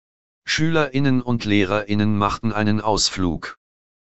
Populär ist vor allem die freie Software NVDA (NonVisual Desktop Access), der auch für die folgenden Audiobeispiele genutzt wird.
Eine männliche Computerstimme (NVDA) liest: Schülerinnen und Lehrerinnen machten einen Ausflug. Wie das Binnen-I wird der Mediopunkt vom Screenreader nicht beachtet.